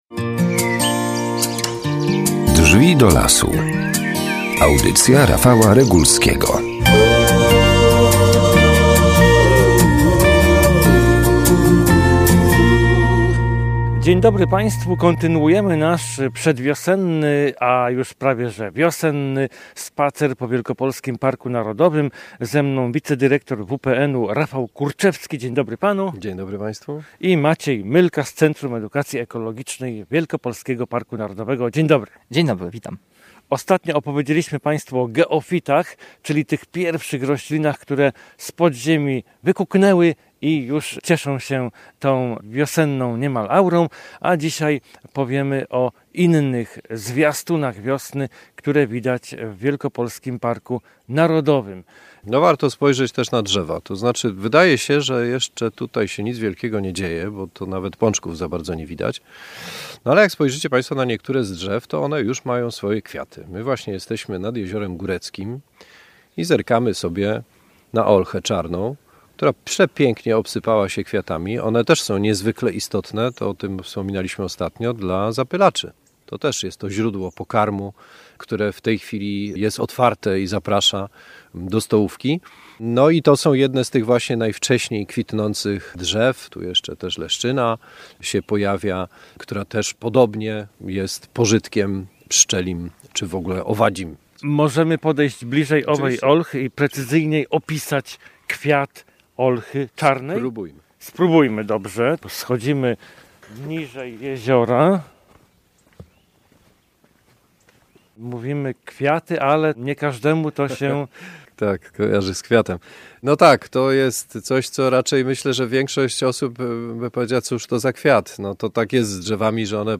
W poszukiwaniu, a raczej rozpoznawaniu oznak wiosny staniemy dziś pod kwitnącym drzewem, wsłuchamy się w trel ptaków i zapytamy: co teraz robią płazy? A wszystko to na terenie Wielkopolskiego Parku Narodowego.